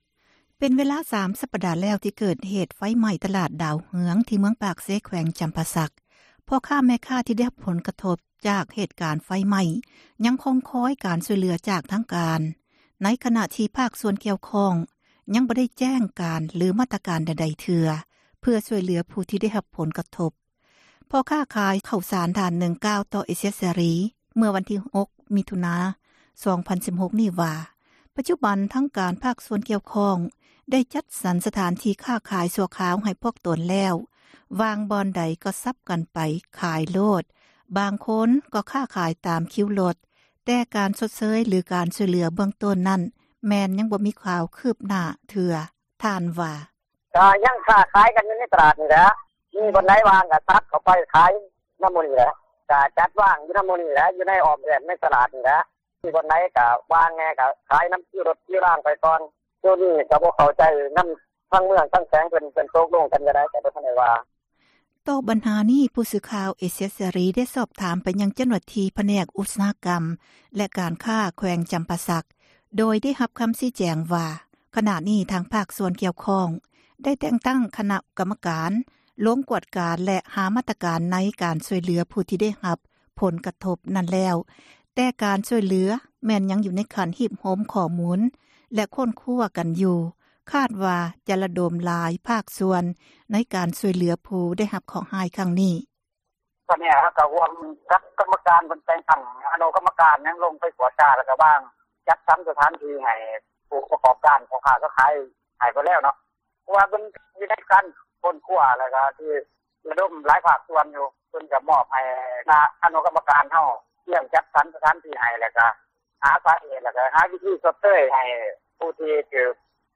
ພໍ່ຄ້າ ຂາຍເຂົ້າສານ ທ່ານນຶ່ງ ກ່າວຕໍ່ ເອເຊັຽເສຣີ ເມື່ອວັນທີ 6 ມິຖຸນາ 2016 ນີ້ວ່າ ປະຈຸບັນ ທາງພາກສ່ວນ ກ່ຽວຂ້ອງ ໄດ້ຈັດສັນ ສະຖານທີ່ ຄ້າຂາຍ ຊົ່ວຄາວ ໃຫ້ແລ້ວ, ມີບ່ອນໃດ ກໍ່ສັບກັນເຂົ້າ ໄປຂາຍໂລດ ບາງຄົນ ກໍ່ຄ້າຂາຍ ຕາມຄິວຣົດ, ແຕ່ການ ຊົດເຊີຍ ຫຼື ການຊ່ວຍເຫຼືອ ເບື້ອງຕົ້ນ ນັ້ນ ແມ່ນຍັງ ບໍ່ມີຂ່າວ ຄຶບໜ້າເທື່ອ ທ່ານວ່າ: